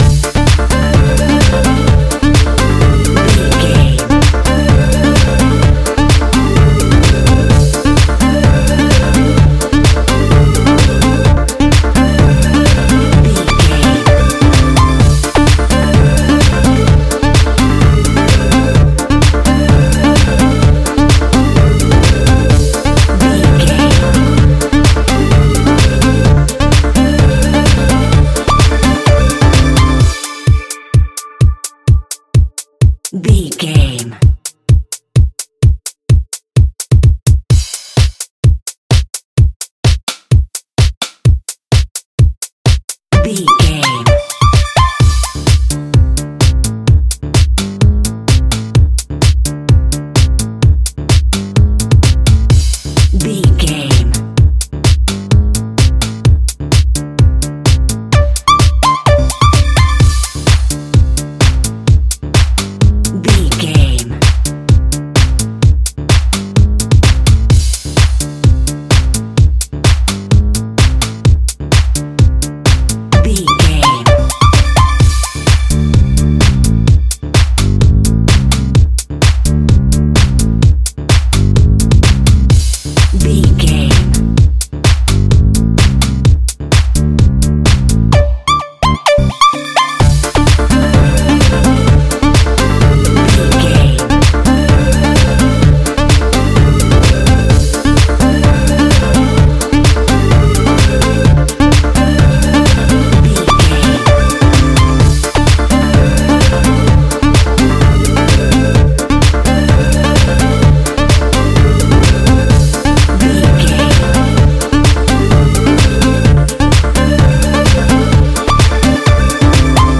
Aeolian/Minor
D
energetic
uplifting
hypnotic
drum machine
synthesiser
electro house
joyful